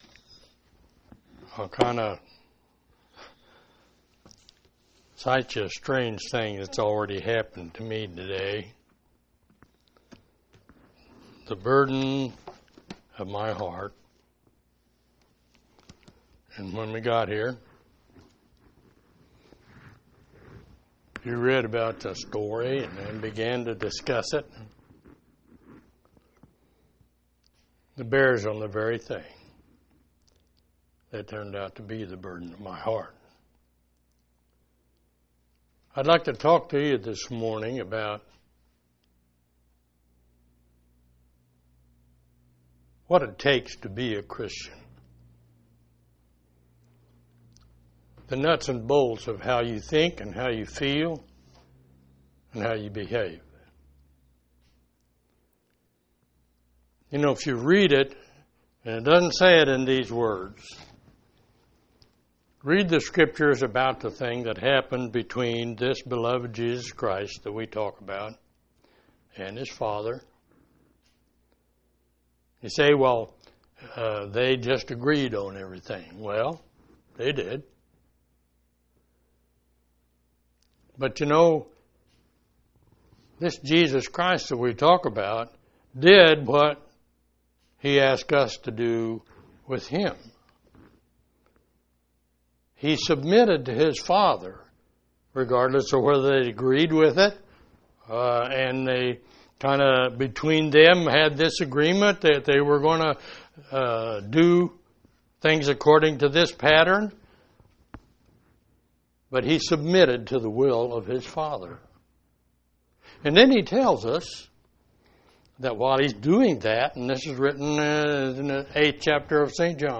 3/20/2016 Location: Collins Local Event